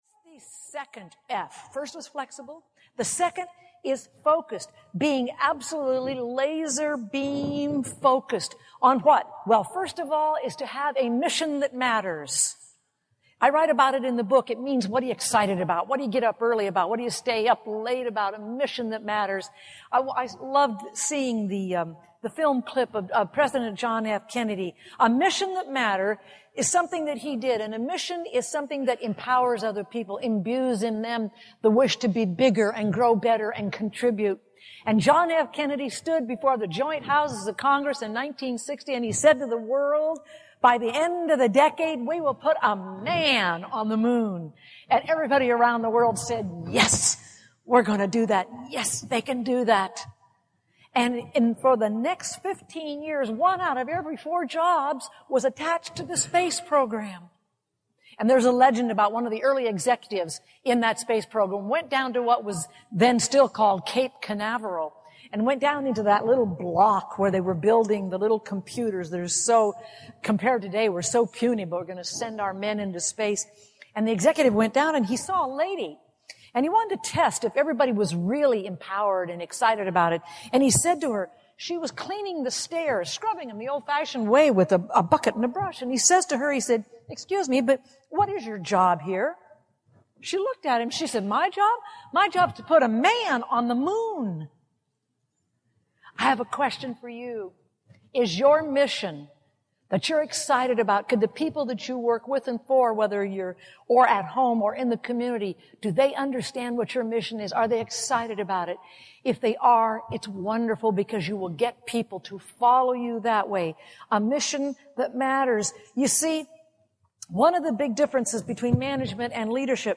What Is Leadership? Audiobook